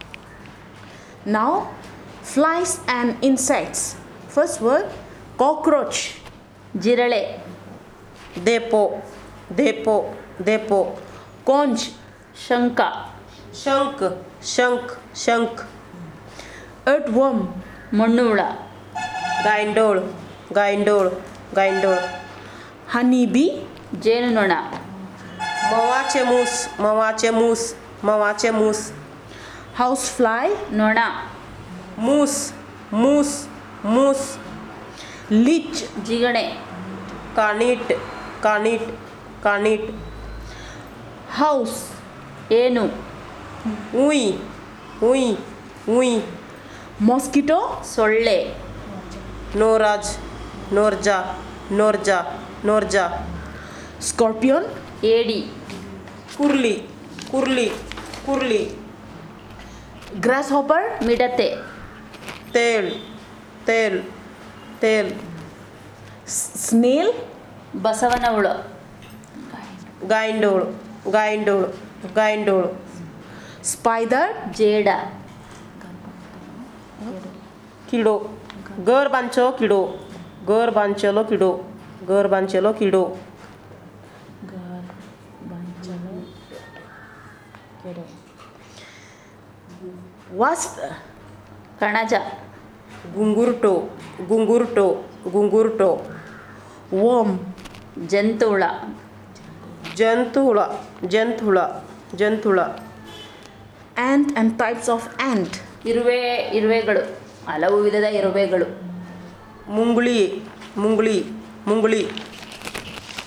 Elicitation of words about flies and insects